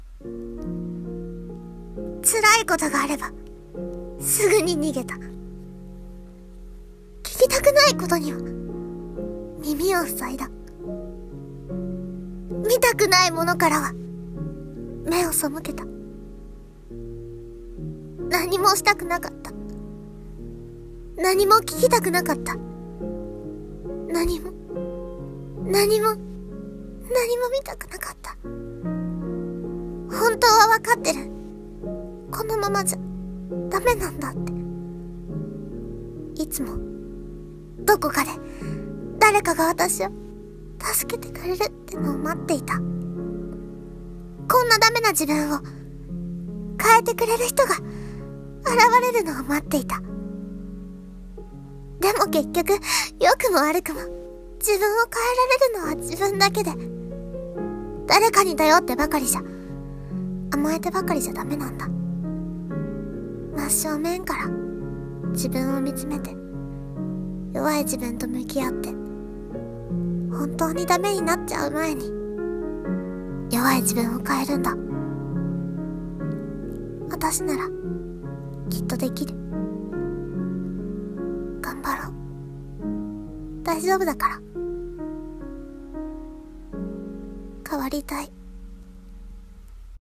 [朗読･声劇]自分自身で